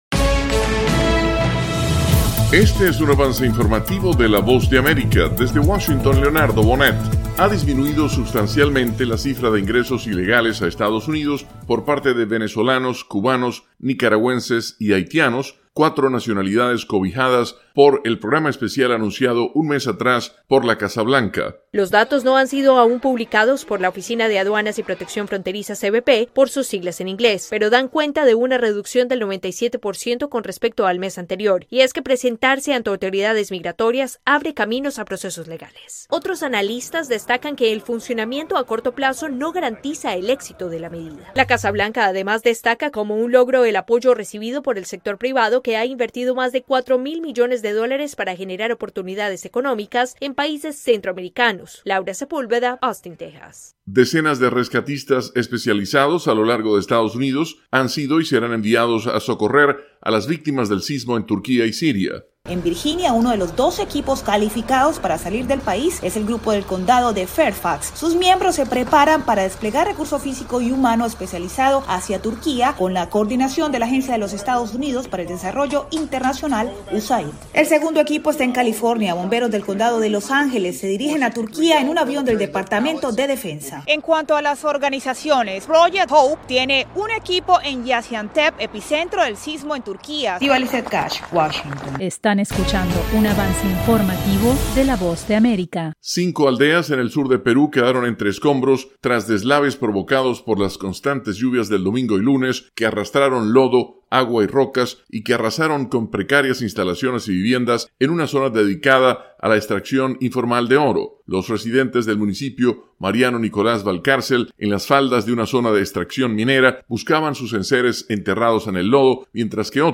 Avance Informativo 7:00 PM